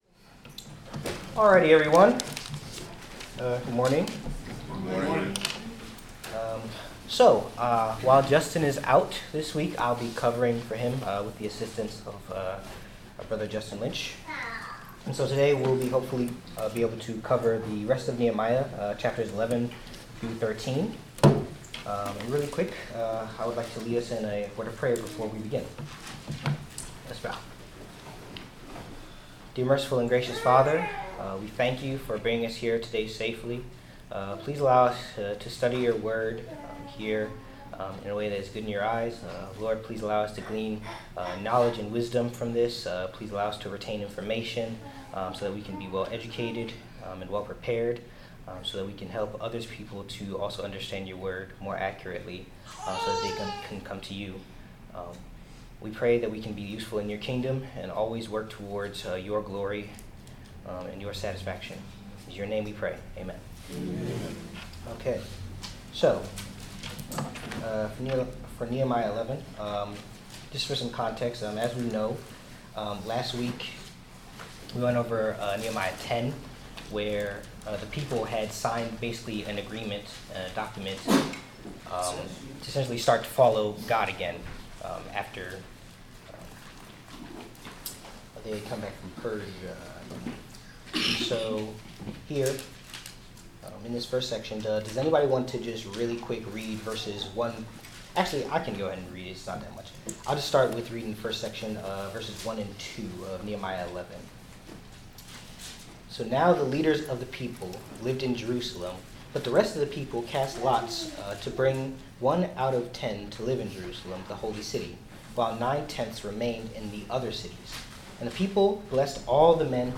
Bible class: Nehemiah 11-13
Service Type: Bible Class